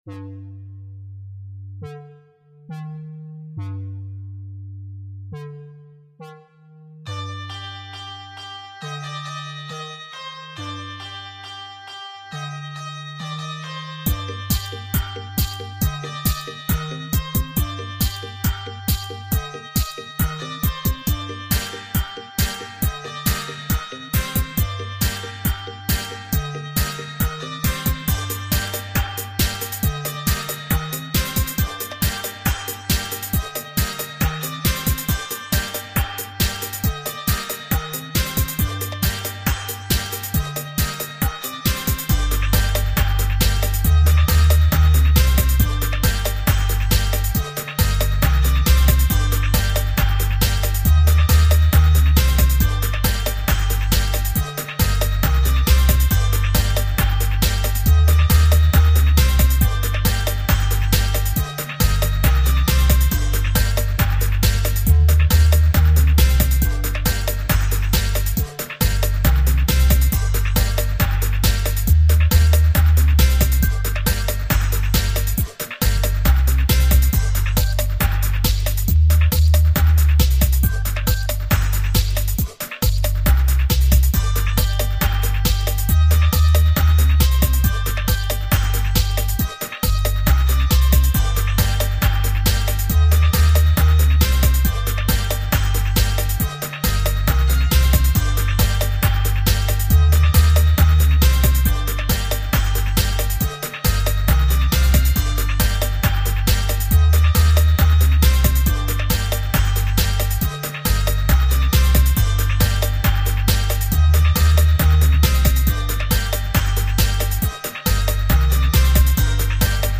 Factory style